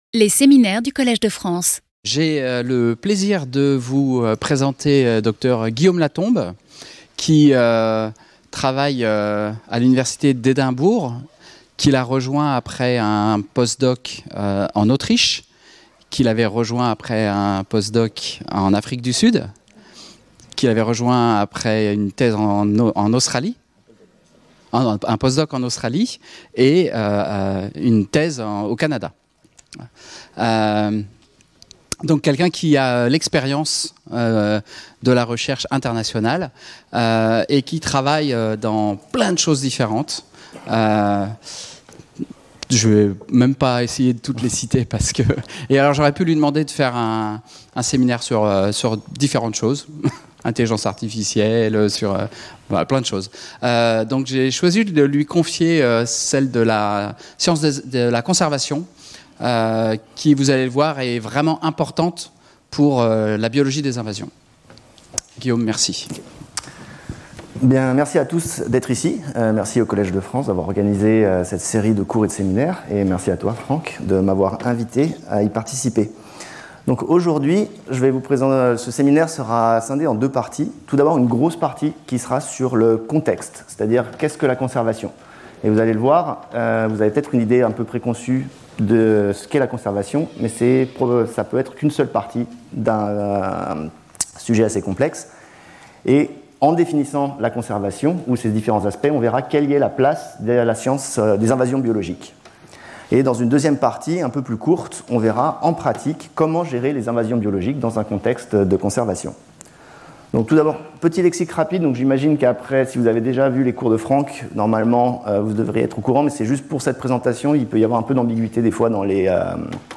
In this seminar, I'll be giving you an overview of the different approaches to conservation that exist in the Western world, and their underlying practical or philosophical principles. This will enable us to better understand the place of biological invasions in these different perspectives. Finally, I will outline the strategies employed to control or eradicate an invasive species when deemed necessary.